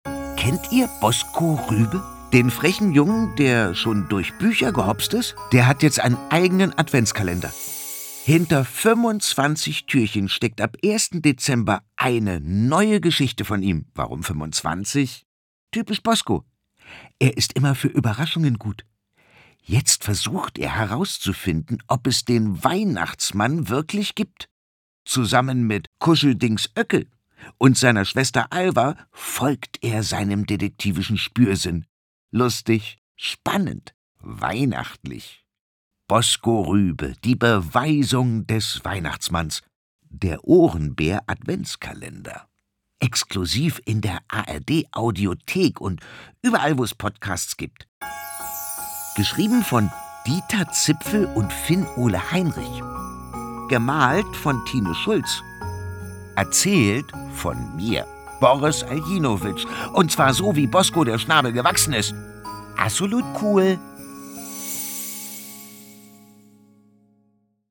Aljinovic, und zwar so, wie Bosco der Schnabel gewachsen ist: